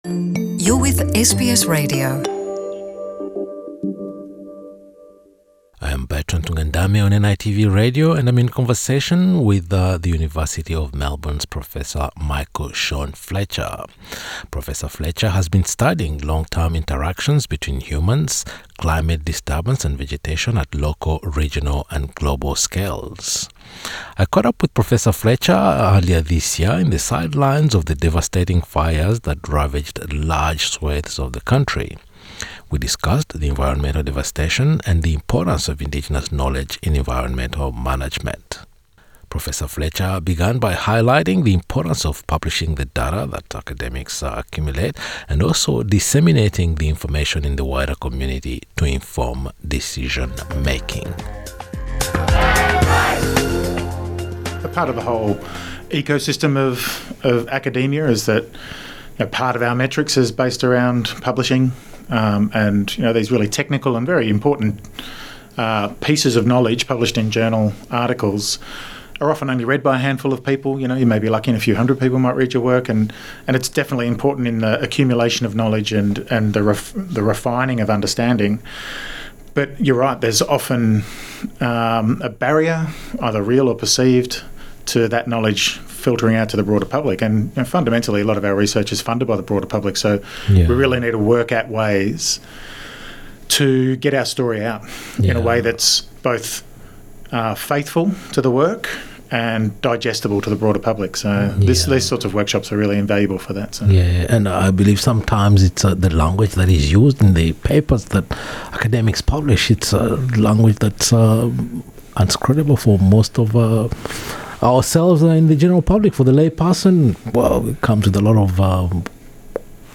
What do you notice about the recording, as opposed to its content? This conversation was recorded in the sidelines of the bushfires that devastated large swathes of the country at the end of 2019 early 2020. We discussed how Aboriginal knowledge and land management practices have protected this country over thousands of years.